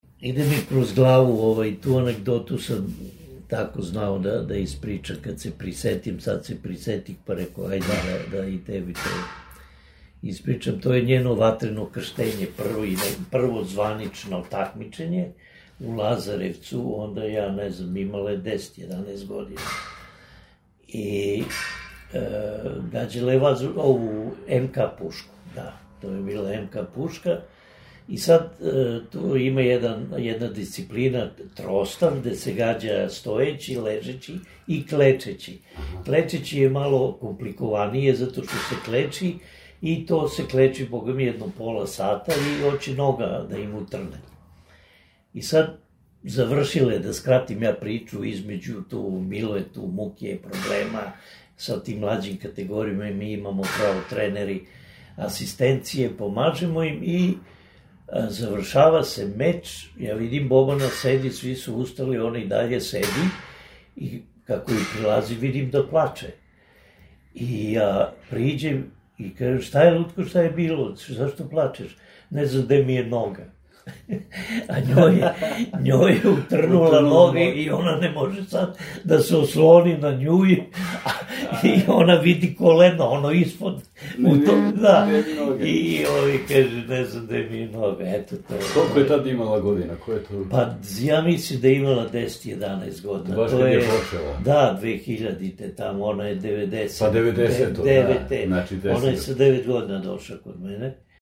Ово је сажетак, а ако желите чути изворну изјаву, можете ову комплетно препричану анегдоту преслушати у доле приложеном аудио запису: